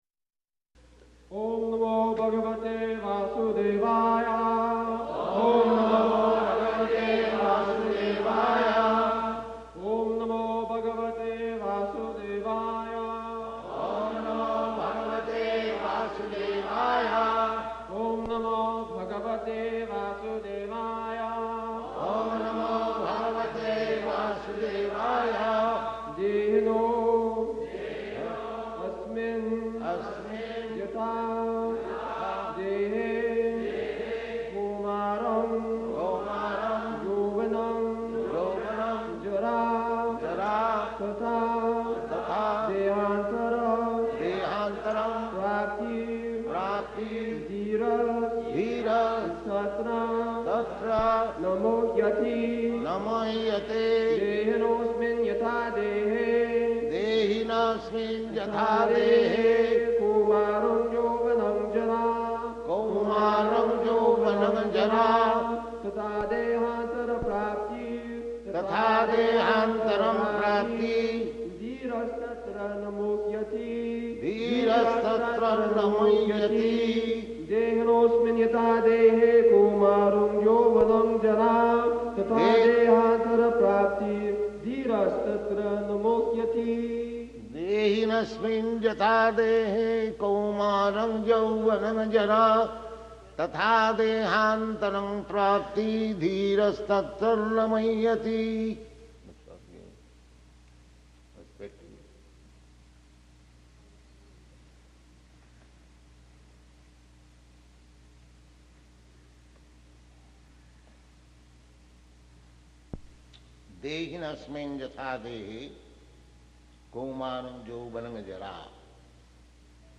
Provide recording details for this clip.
September 8th 1972 Location: Pittsburgh Audio file